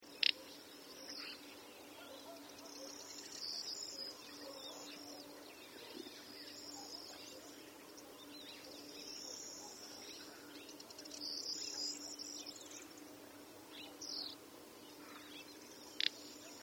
Une matinée avec l’outarde canepetière
Dès le lever du jour, ce sont plusieurs mâles qui trompettent autour de moi. Leur chant pourrait être traduit par de brefs et rapides « prett ». Entre nous, cela ressemble plus à de petits pets…